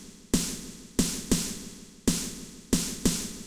REVERB SD -L.wav